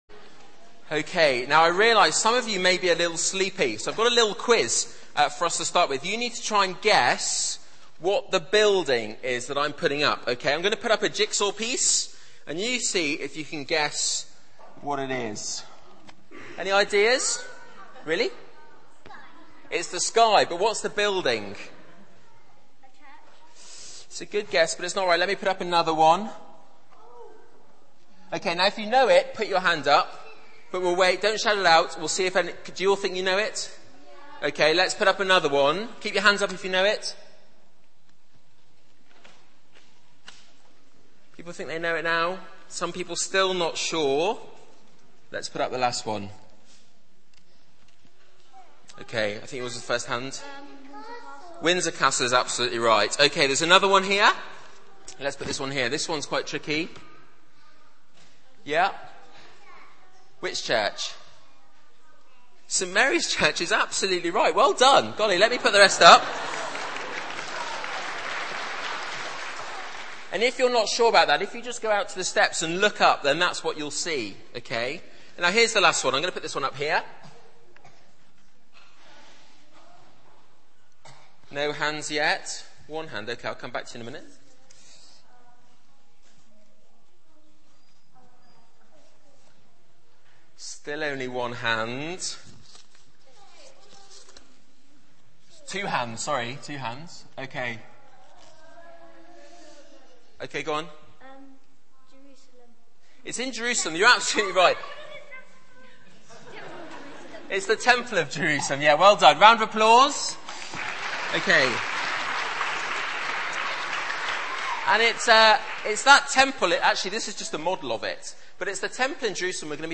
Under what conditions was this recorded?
Media for 9:15am Service on Sun 29th Aug 2010 11:00 Speaker